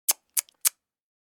Tsk Sound Effect
Description: Tsk sound effect. A man makes a sharp “tsk, tsk, tsk” sound. He shows clear disappointment and disapproval.
Tsk-sound-effect.mp3